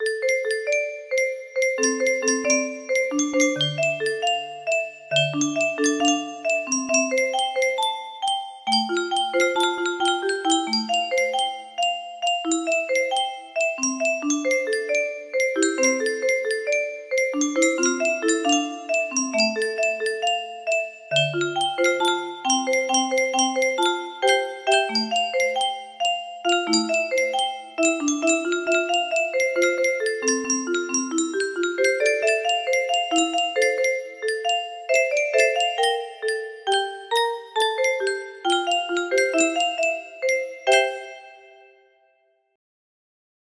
Edited for grand illusion 30ver & shortened